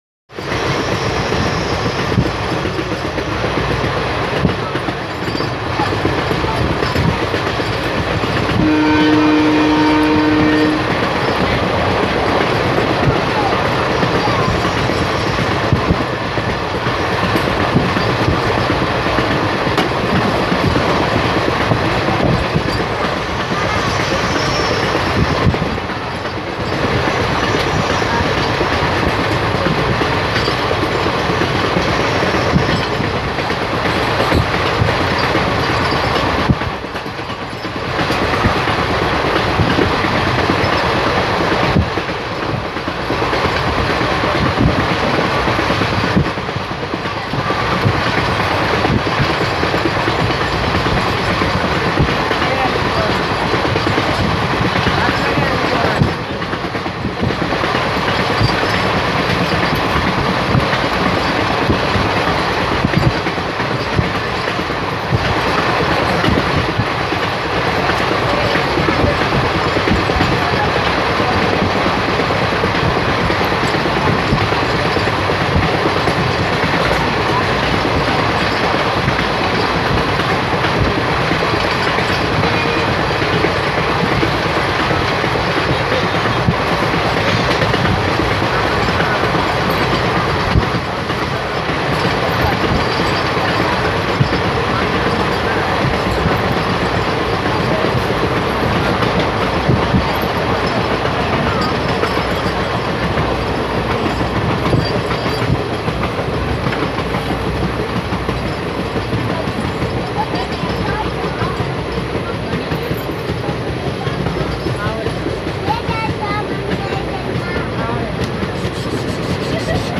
We were soon blasting away with some melodious HT honking (accompanied by flat wheel sounds) before slowing down.
11044-honks-flat-wheels-after-wadi.mp3